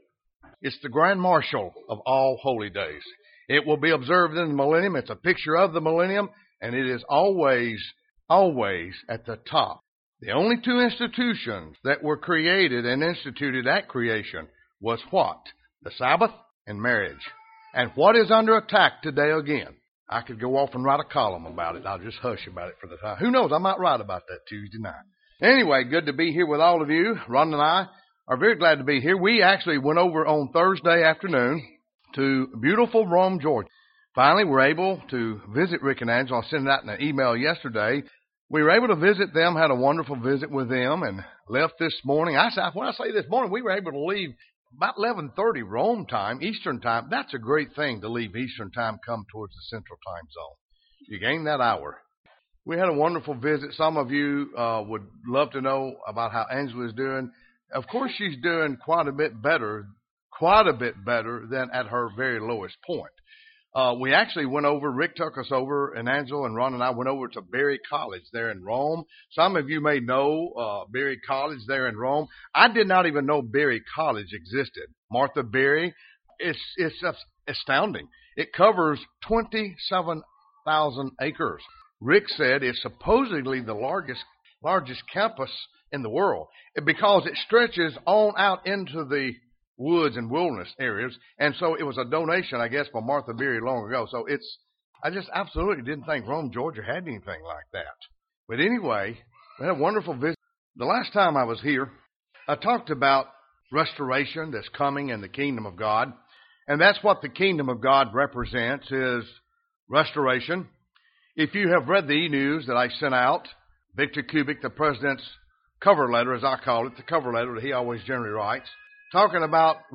Sermons
Given in Birmingham, AL Huntsville, AL